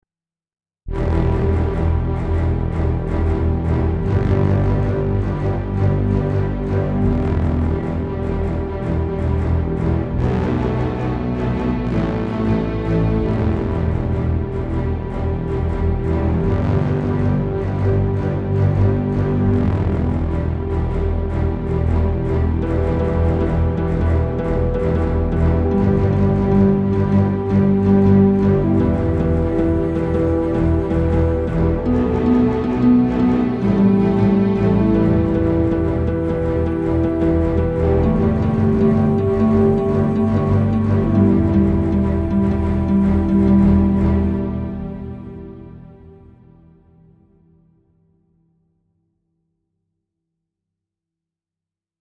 A looping background music for a scifi scene where space ship fleets arrive and the outnumbered ground forces rejoice and their morale sky rocket.